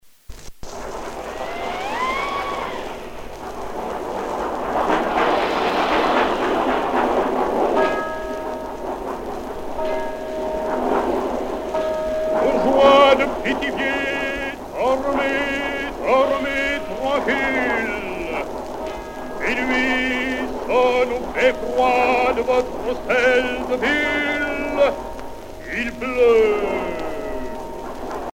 Genre sketch